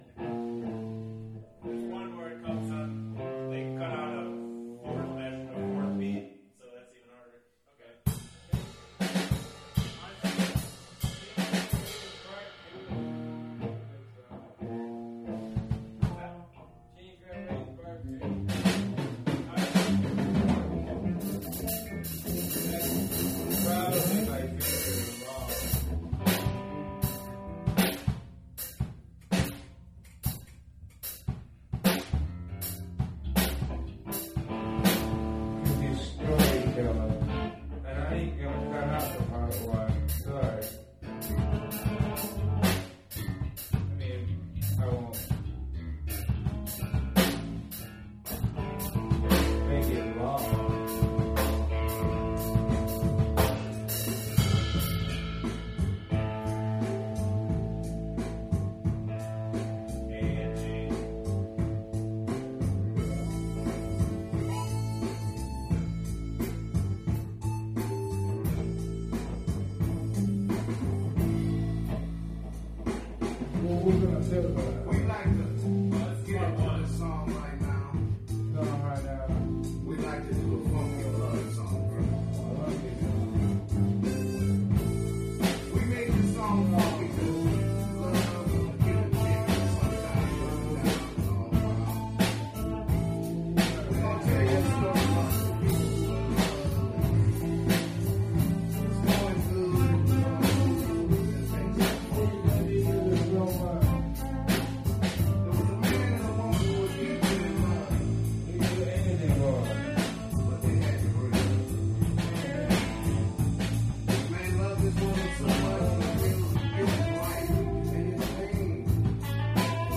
Audio File: KDG rehearsal